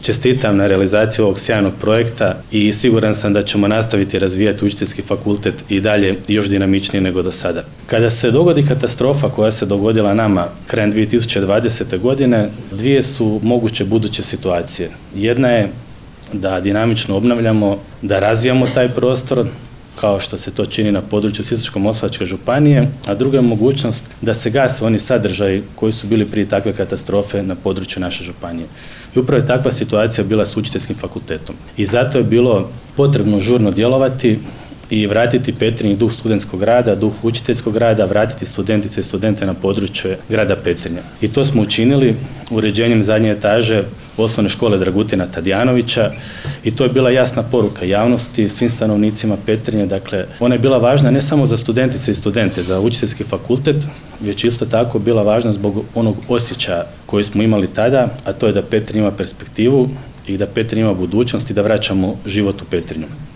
U utorak, 04. ožujka 2025. godine, u Petrinji je svečano otvorena novoobnovljena zgrada Učiteljskog fakulteta – Odsjek u Petrinji.
Župan Ivan Celjak